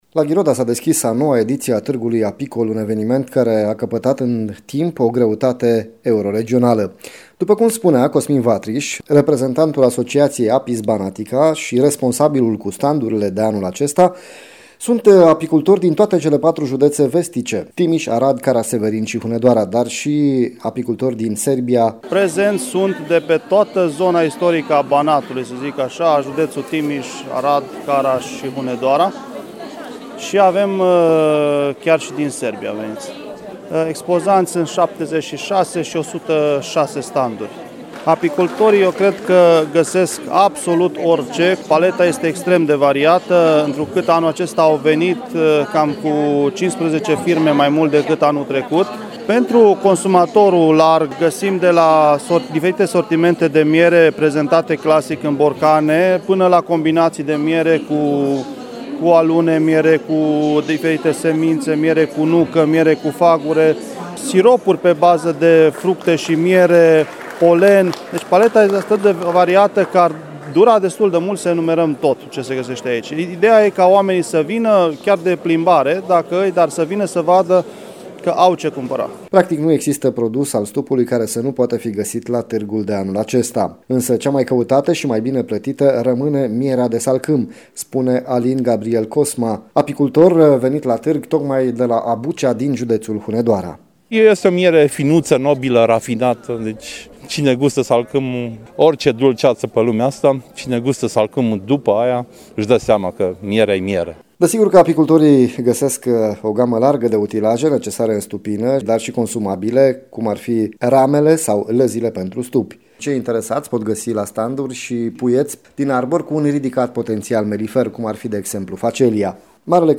La Ghiroda, lângă Timișoara, s-a deschis Târgul Apicol, ajuns la cea de-a noua ediție.